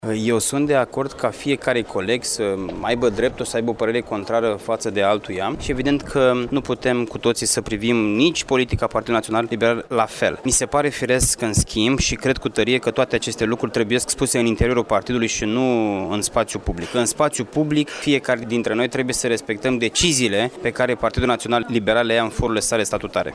Referindu-se la poziţiile divergente din ultima perioadă din interiorul PNL Iaşi, Costel Alexe a declarat că este de acord cu prezentarea poziţiei fiecăruia, dar în cadrul partidului şi nu în presă: